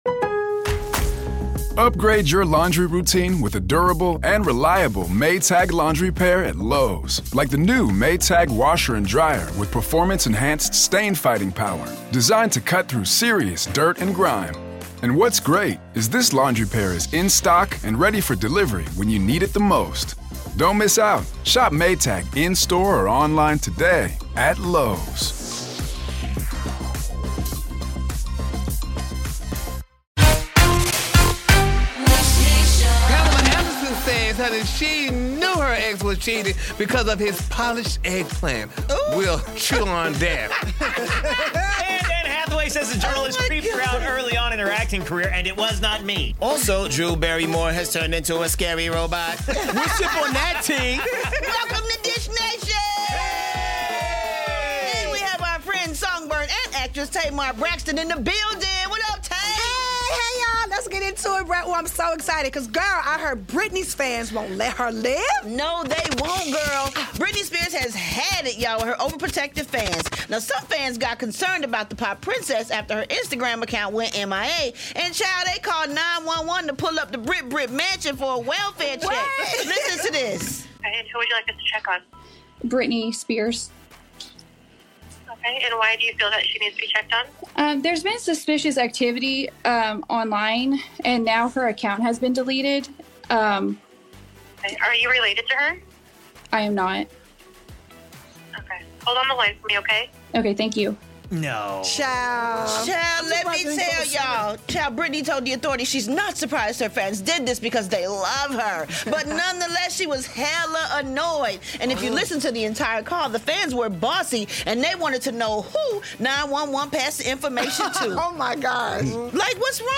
Chris Brown's clothes collection gets its own department store! Plus, find out why Britney Spears fans called 911, and you'll never guess who got nominated for a Razzie Award. Our girl Tamar Braxton co-hosts, so grab your teacup and tune in to today's Dish Nation for a sip.